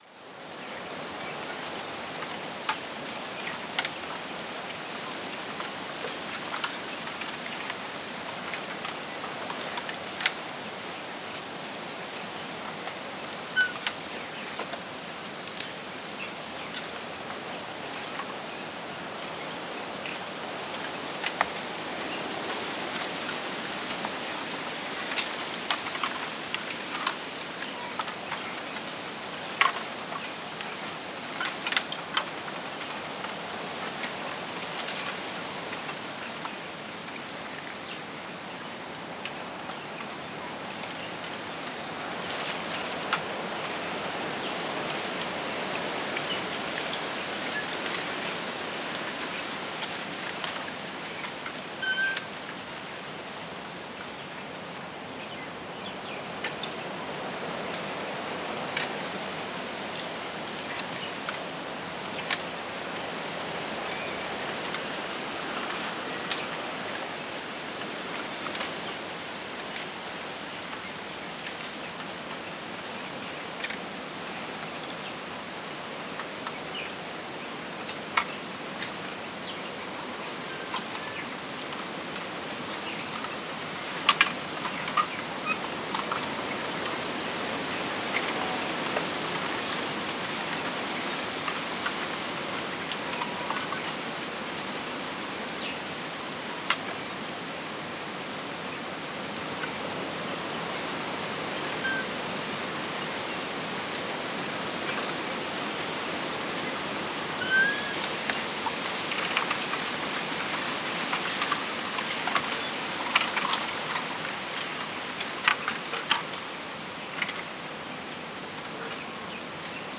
High_bamboo.L.wav